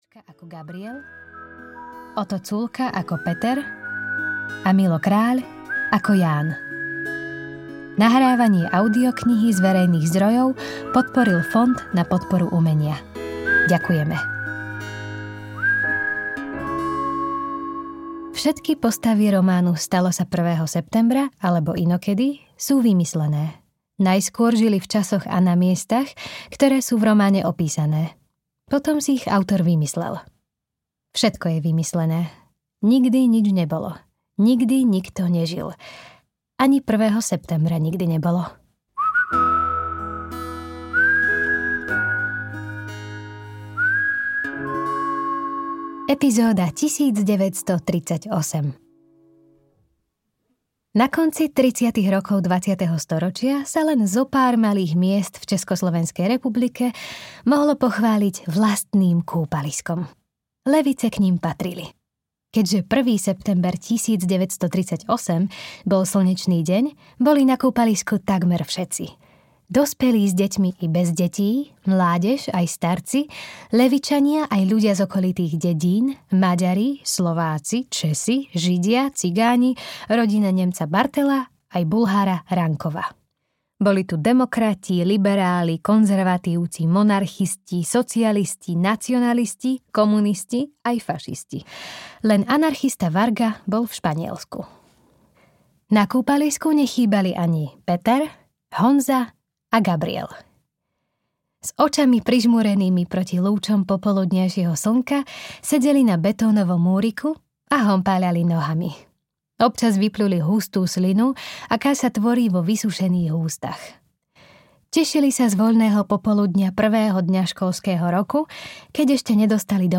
Stalo sa prvého septembra (alebo inokedy) audiokniha
Ukázka z knihy